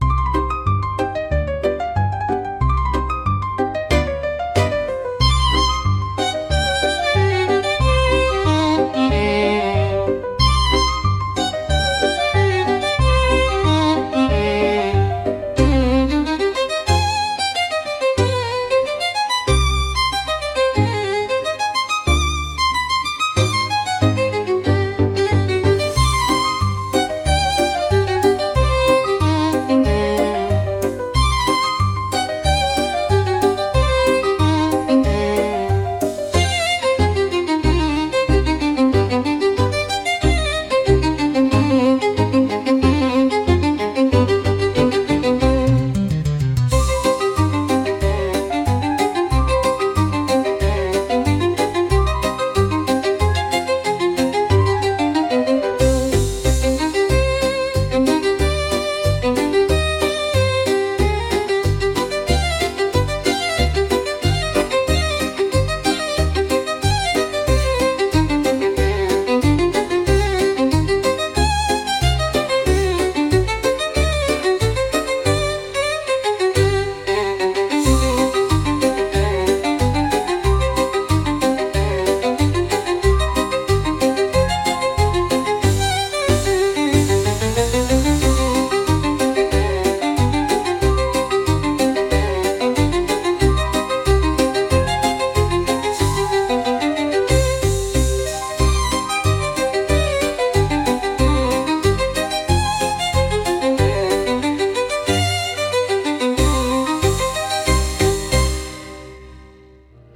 Instrumental / 歌なし
表情豊かなバイオリンをメインに、流れるようなピアノが寄り添う美しい一曲。
一聴すると繊細で美しい旋律ですが、その奥には芯の通った「強さ」を秘めています。
速すぎないテンポでリズムが取りやすく、一つ一つの動きを大切にする演技に最適。